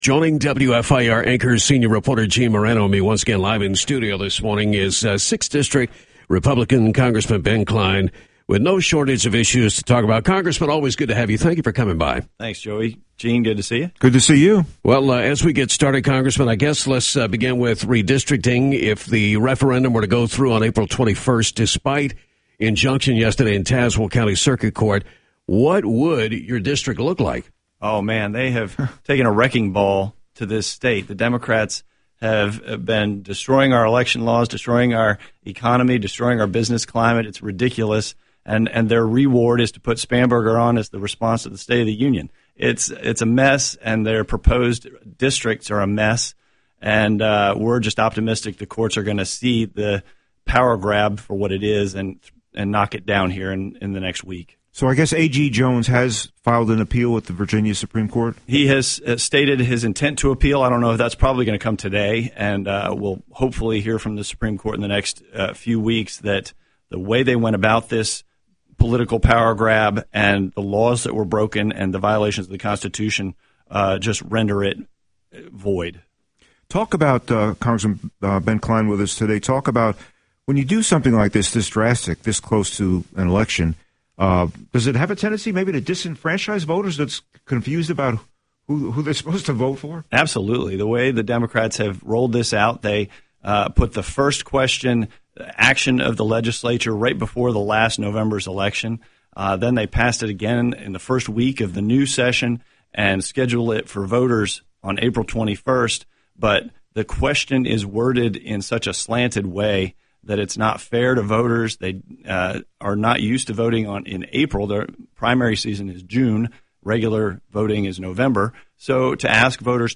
Live in studio this morning was 6th District Republican Congressman Ben Cline on redistricting, if the referendum were to go through on April 21st despite injunction yesterday in Tazewell County circuit court – what would your district look like?
2-20-Cline-live-in-studio-WEB.mp3